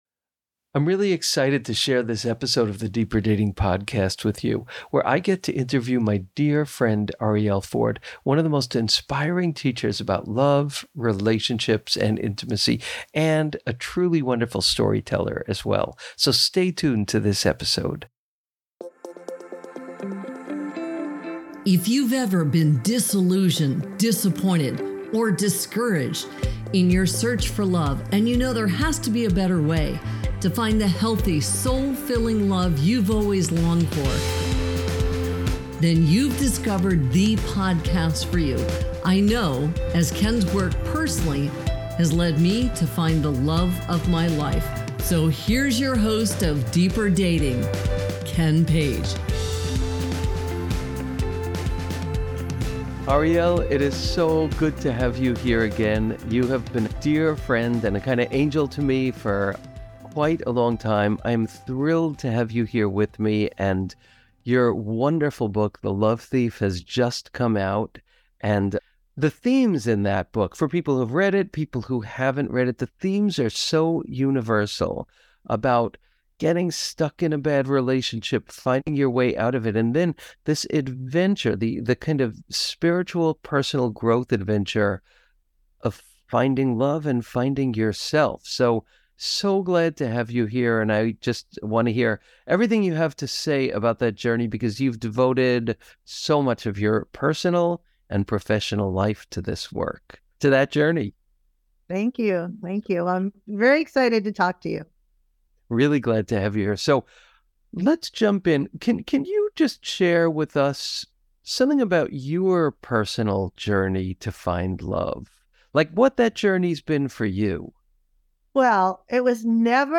I'm so excited to share this interview with my dear friend Arielle Ford, one of the most inspiring teachers (and storytellers) about love, relationships, and intimacy. Arielle shares her personal story and her practical yet magical vision of how we find true love.